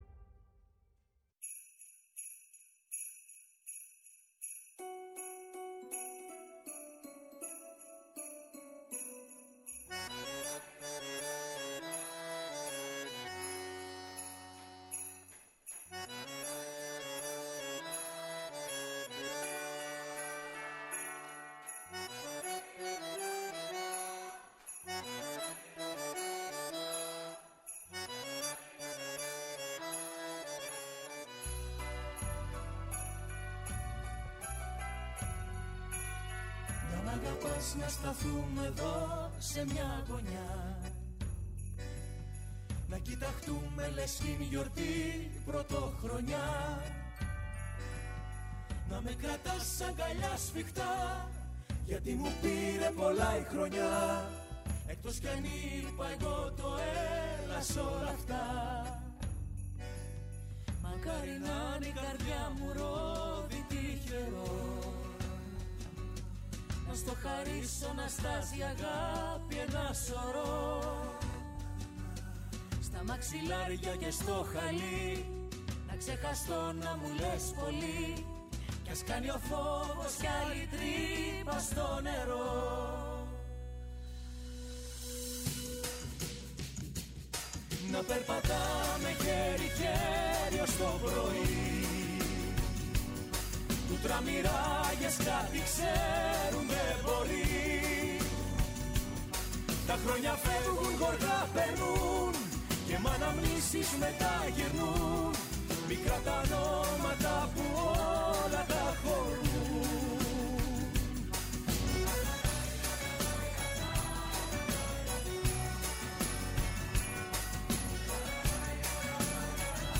Στην εκπομπή φιλοξενήθηκαν τηλεφωνικά